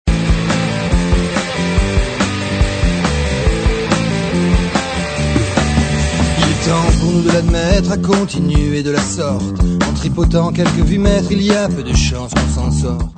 chanson influences diverses